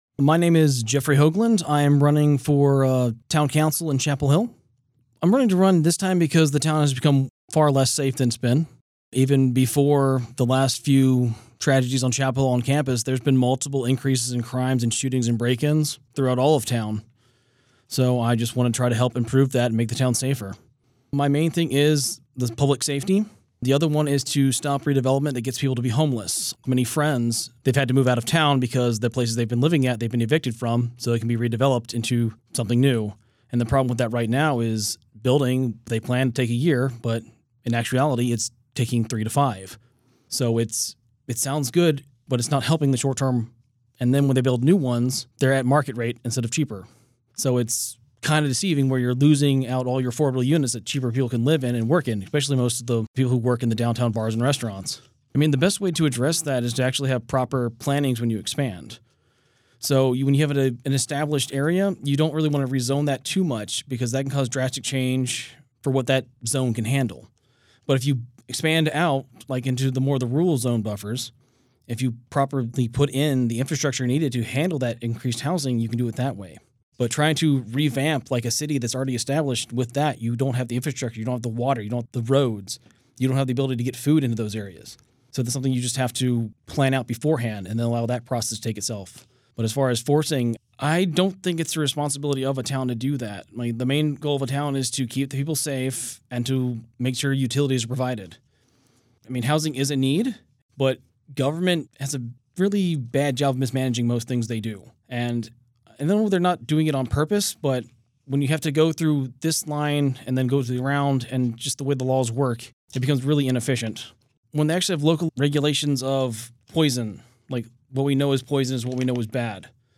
During this local election season, 97.9 The Hill and Chapelboro spoke with candidates for races representing Chapel Hill, Carrboro and Hillsborough.
Their answers (lightly edited for clarity and brevity) are shared here, as well as links to their respective campaign websites or pages.